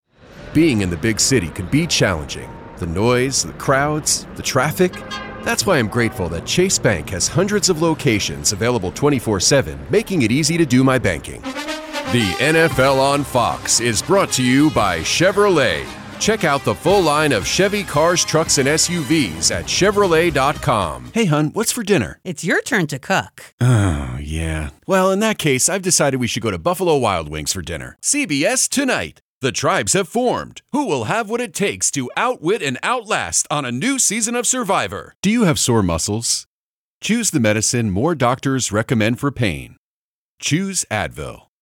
Demo
Young Adult, Adult
I have a warm, natural, guy next door, baritone voice. I'm also able to do high energy reads, as well as movie trailer type reads.
COMMERCIAL 💸
warm/friendly